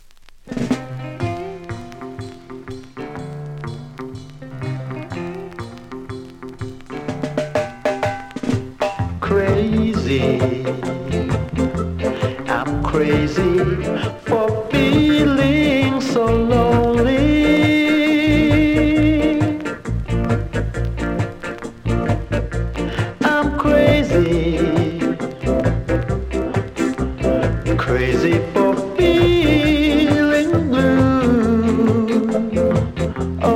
後半キズにより数発大きいノイズあります。